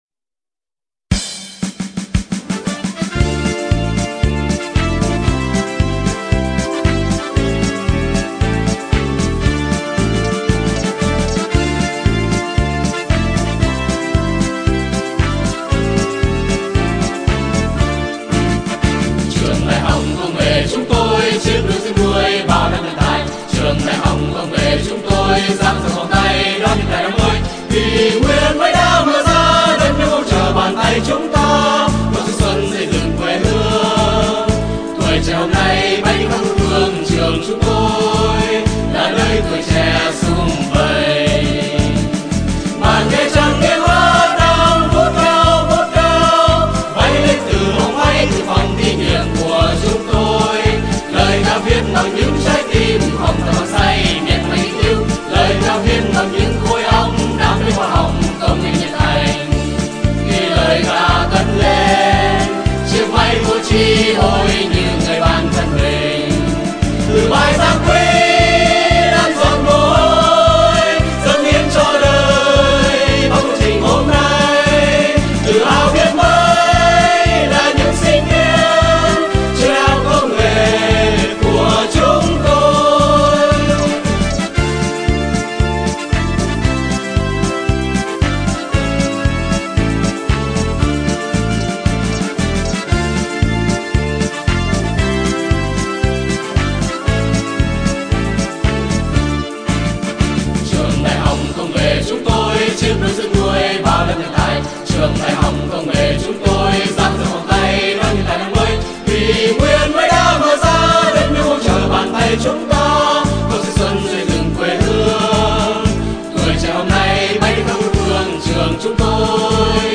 Với sự hỗ trợ điều chỉnh của nhạc công và góp ý của cán bộ Nhà trường, bài hát đã được trình diễn vào Lễ khai giảng năm học 2004-2005 bởi dàn đồng ca là sinh viên, cán bộ Trường.